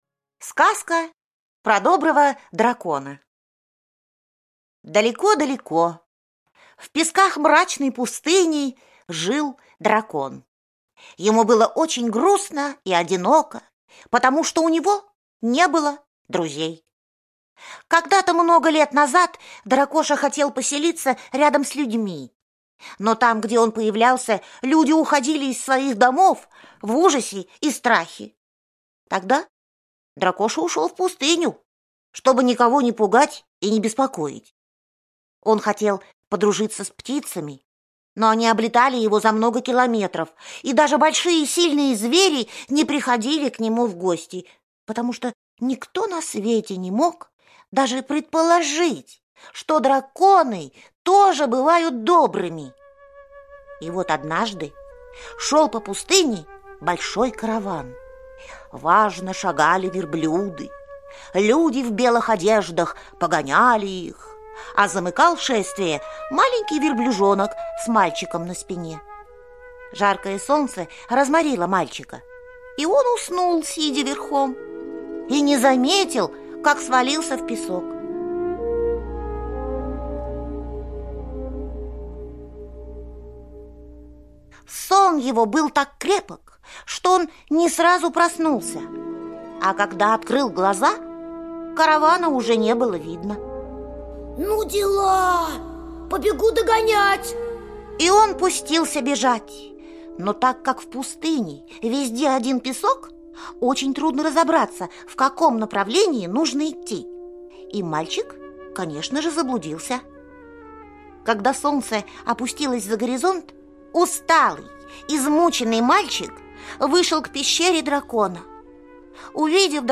Слушайте Сказка про доброго дракона - аудиосказка Онисимовой О. Далеко в песках мрачной пустыни жил дракон.